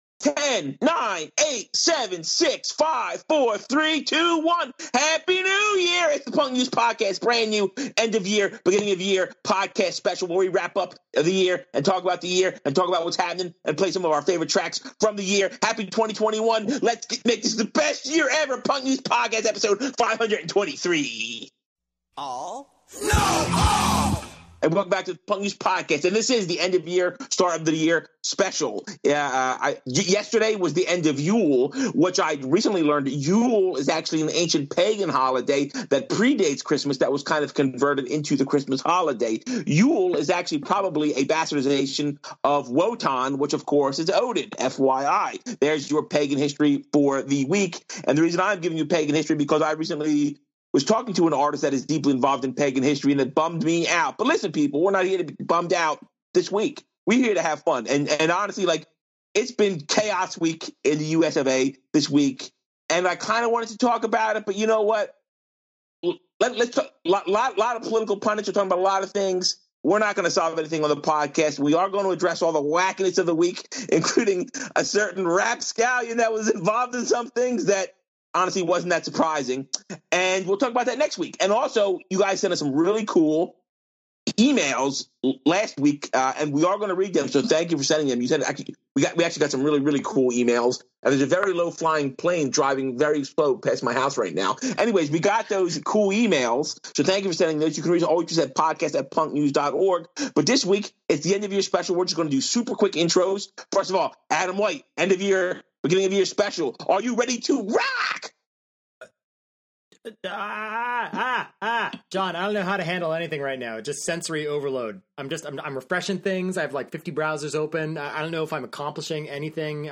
Songs galore.